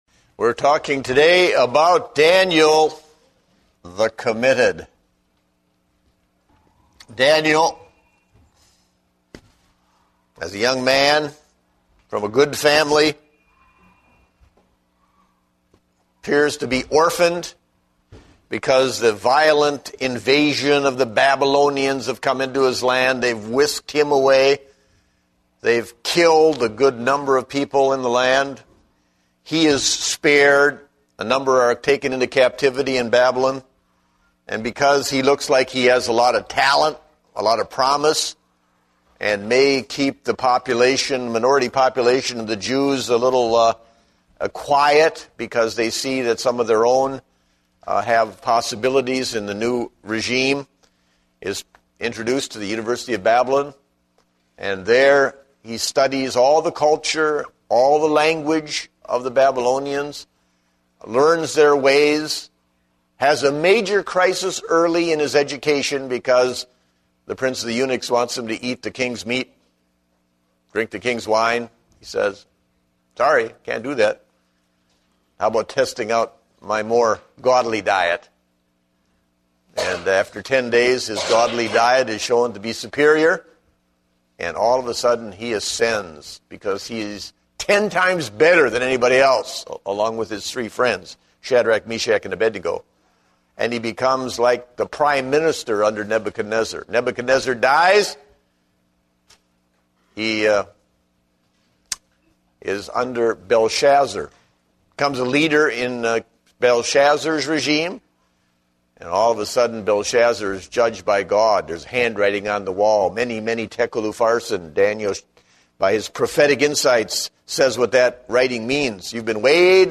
Date: September 26, 2010 (Adult Sunday School)